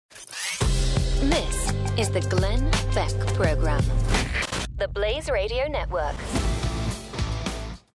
Inglés (Reino Unido)
Imágenes de radio
Micrófono de condensador Neumann TLM 103
Cabina insonorizada con calidad de transmisión con paneles acústicos GIK